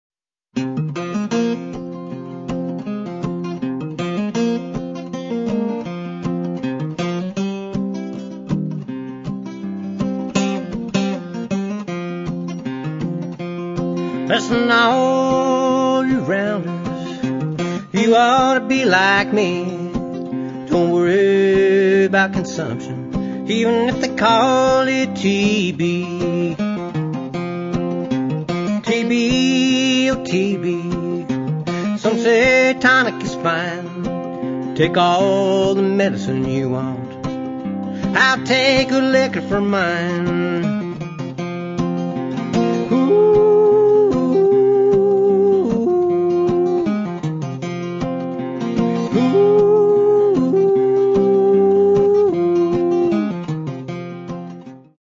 It’s music which sounds warm, sympathetic, and gentle.
We’re talking about blue-grass, country, folk
acoustic guitar